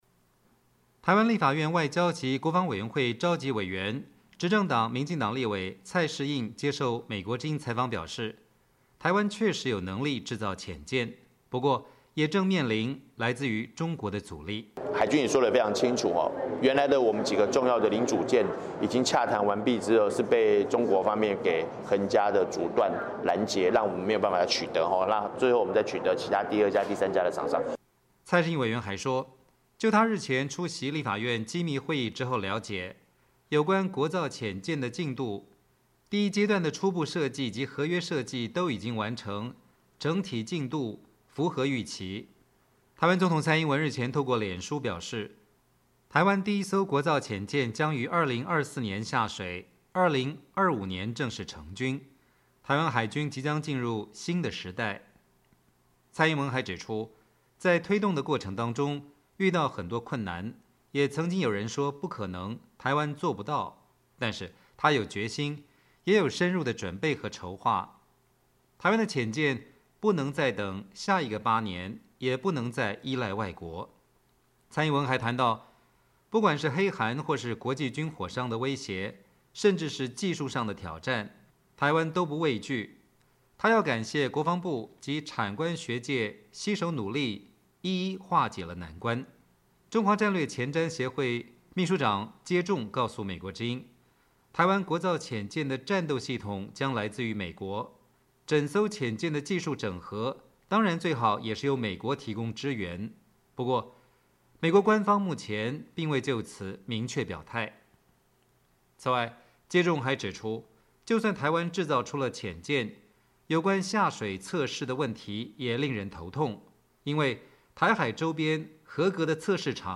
台湾立法院外交及国防委员会召集委员、执政党民进党立委蔡适应接受美国之音采访表示，台湾确实有能力制造潜舰，不过也正面临来自于中国的阻力。